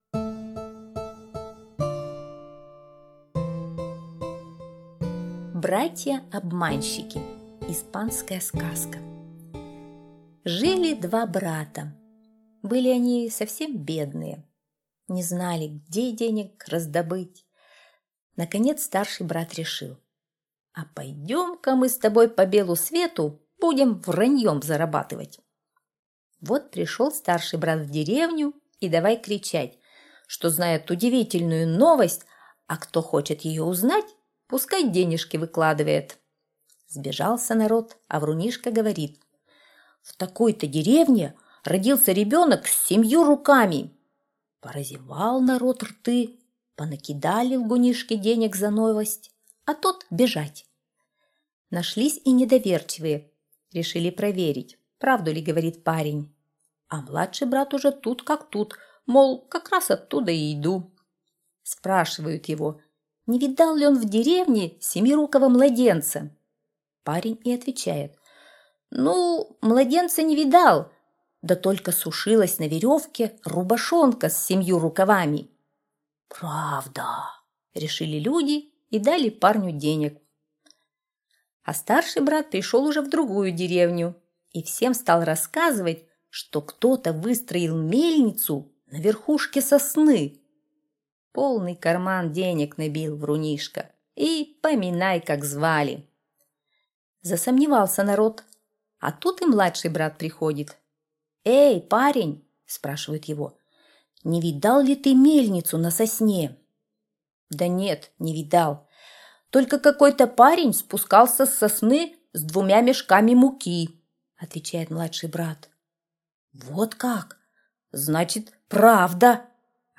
Братья-обманщики — испанская аудиосказка. Сказка про двух братьев, которые были очень бедные.